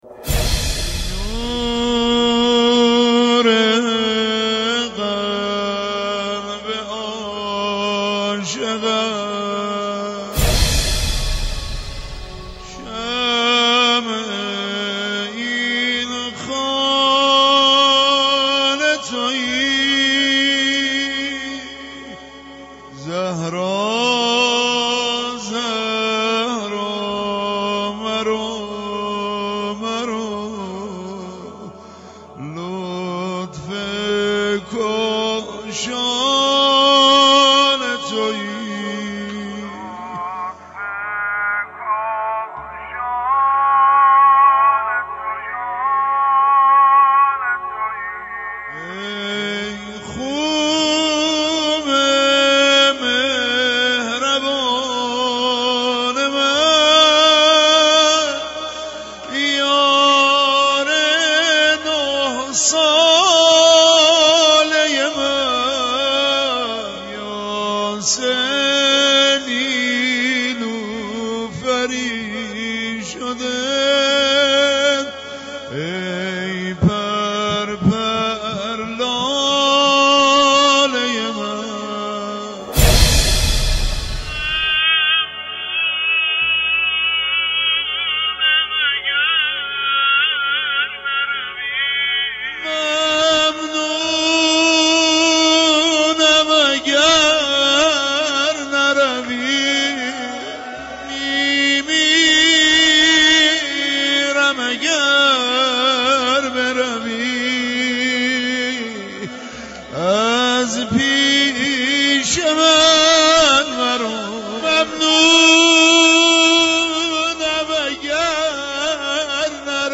کلیپ تنظیم شده